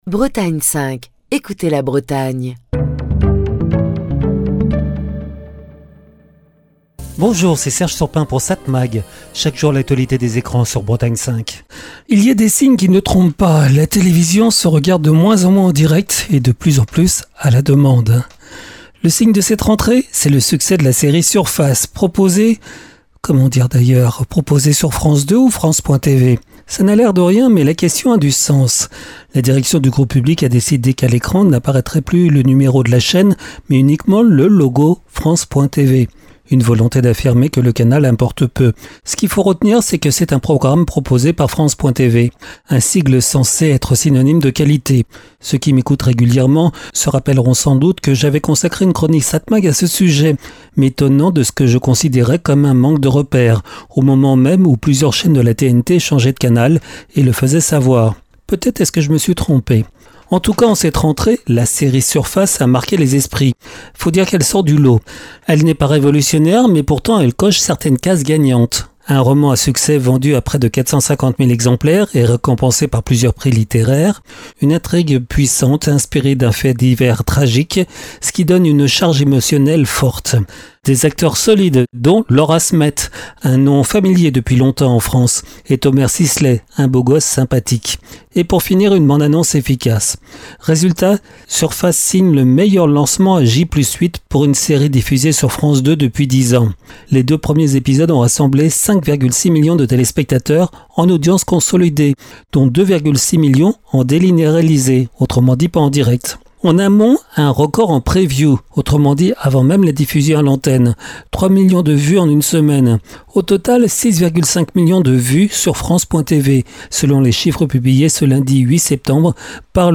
Chronique du 10 septembre 2025.